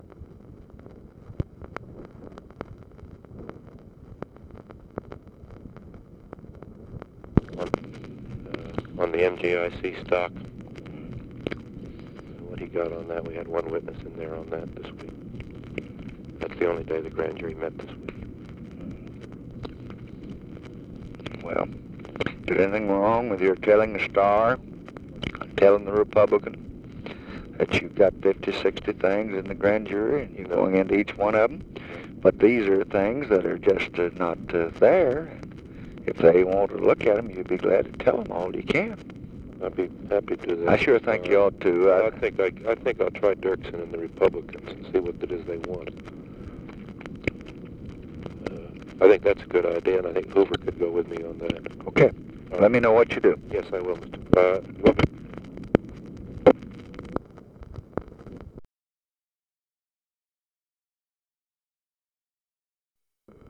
Conversation with NICHOLAS KATZENBACH, March 4, 1965
Secret White House Tapes